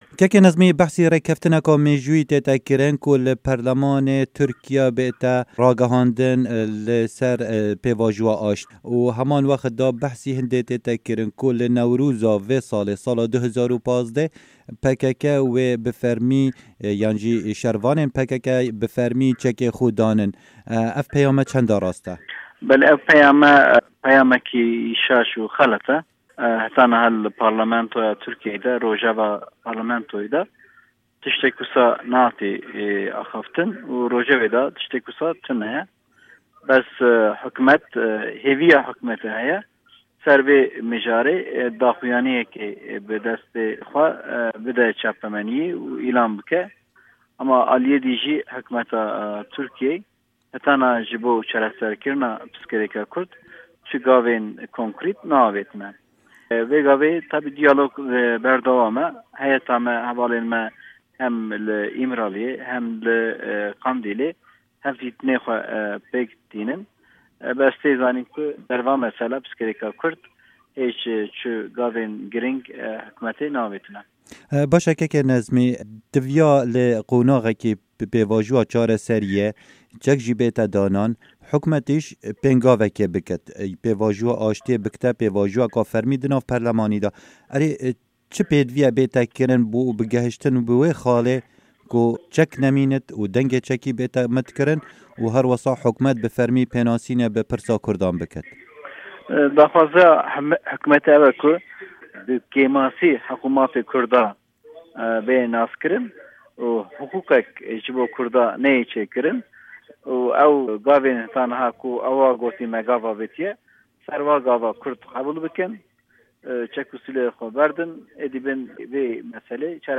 Hevpeyvin digel Nazmî Gur